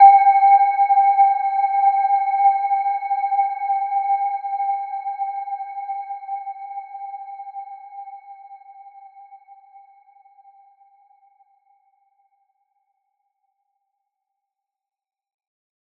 Gentle-Metallic-4-G5-f.wav